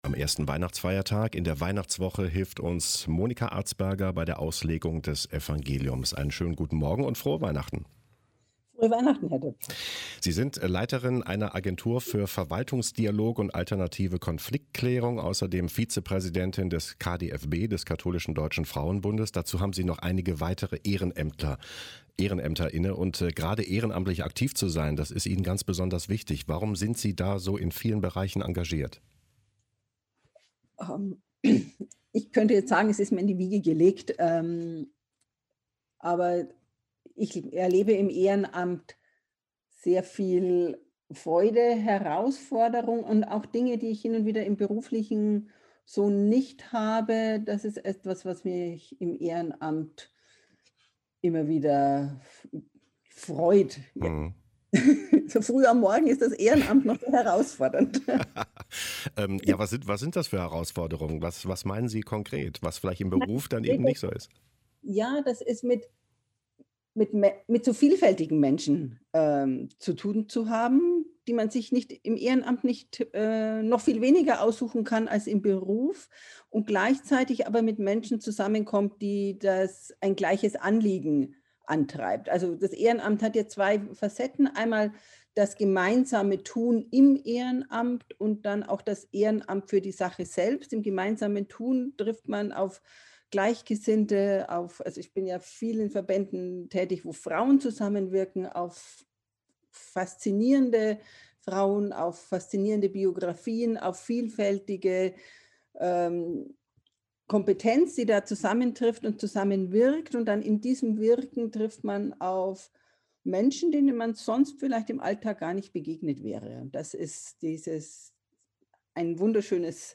Lk 2,15-20 - Gespräch